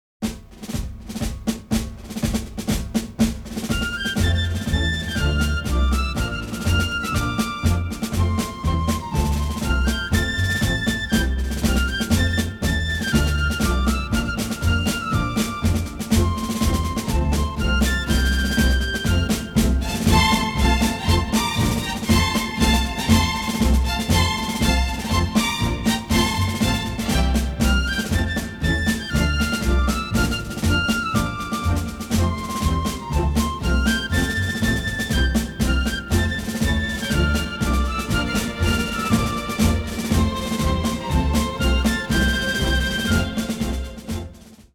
memorable, energetic and varied score